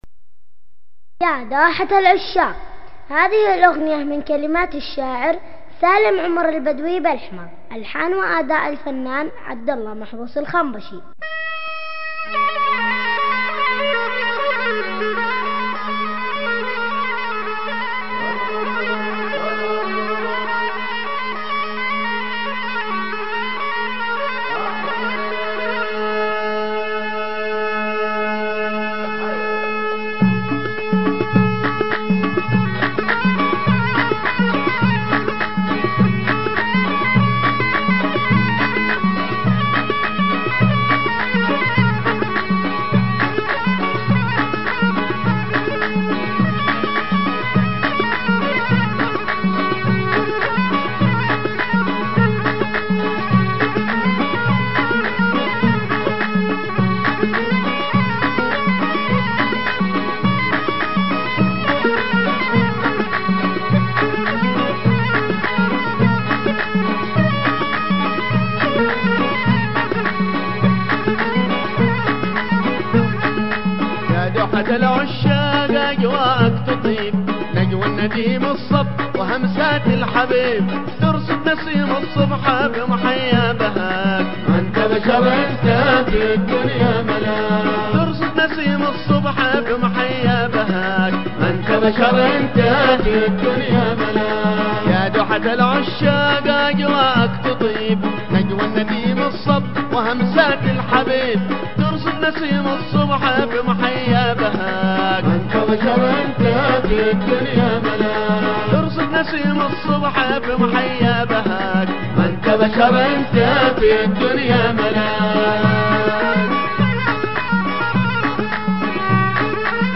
النغم حضرمي واللحن ماشاء الله ماهو مصور
ولحن رائع
وصوت عذب وشجي